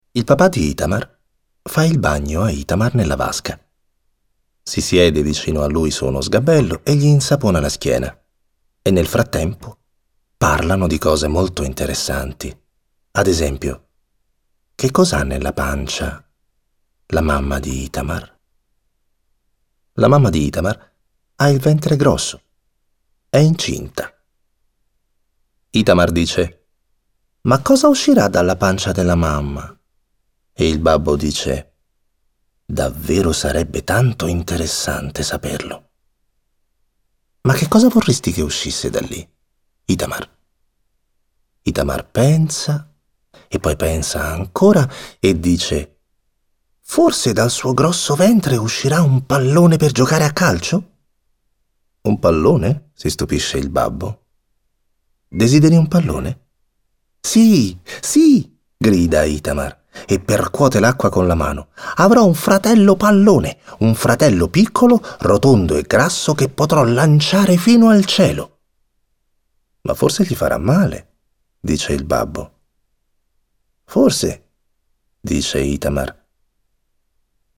letto da Pierfrancesco Favino
«Indimenticabile il vocione di Favino-papà leone» Corriere della Sera
«Storie semplici ed efficaci che Favino recita alla perfezione interpretando un bambino, suo padre e tanti animali.» Il Recensore